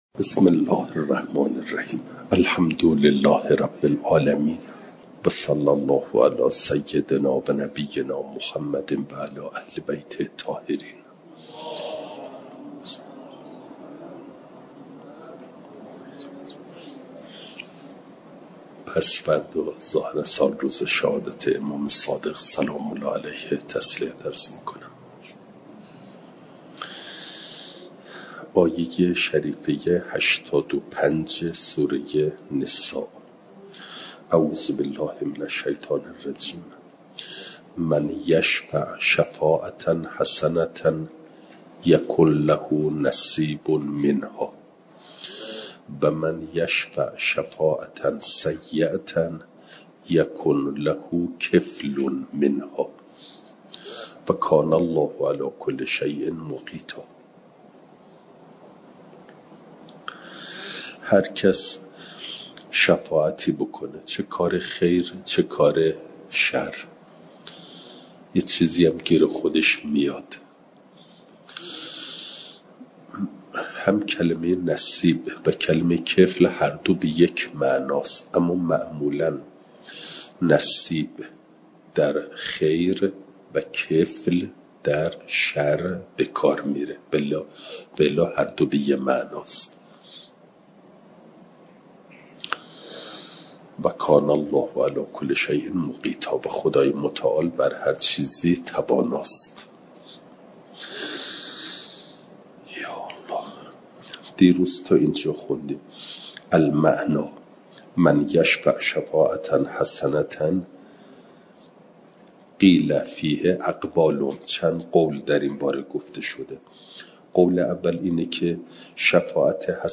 جلسه سیصد و هفتاد و نهم درس تفسیر مجمع البیان